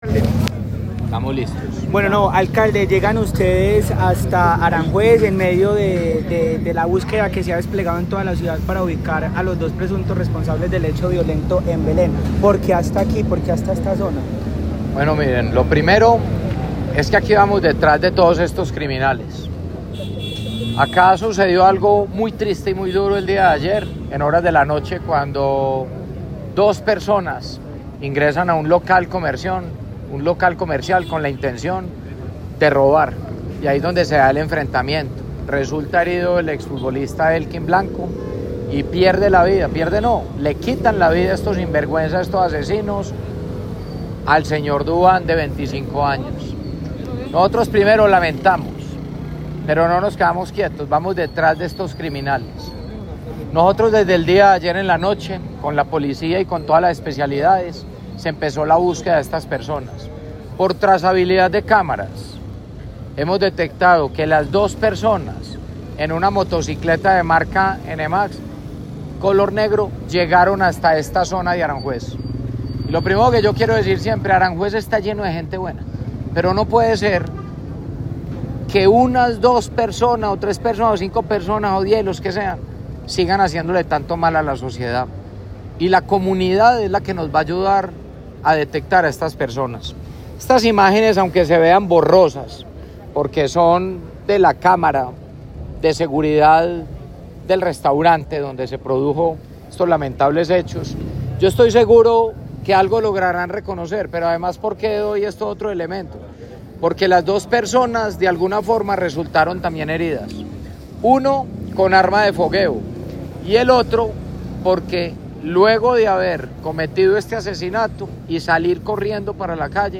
Audio_Alcalde_Homicidio_Belen.mp3